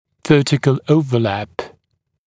[‘vɜːtɪkl ‘əuvəlæp][‘вё:тикл ‘оувэлэп]вертикальное перекрытие